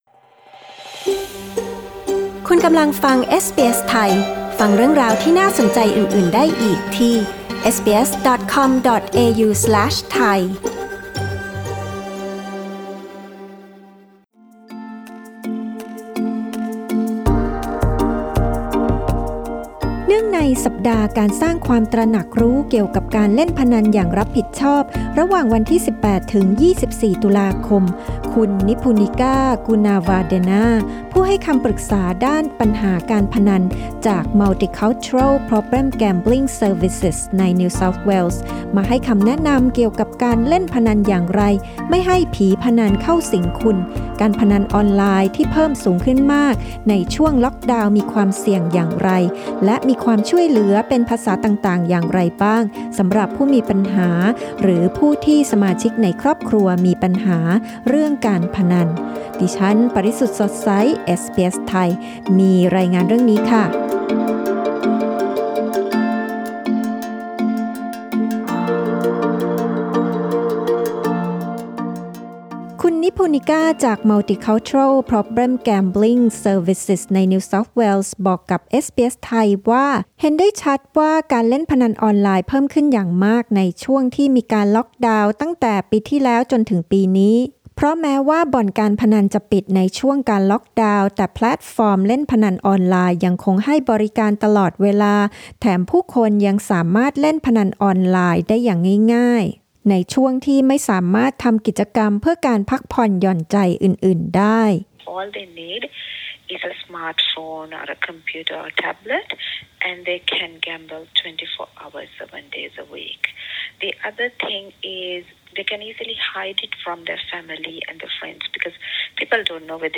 ฟังสัมภาษณ์ภาษาไทย